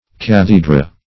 cathedra - definition of cathedra - synonyms, pronunciation, spelling from Free Dictionary
Cathedra \Cath"e*dra\, n. [L., fr. Gr. ? seat. See Chair.]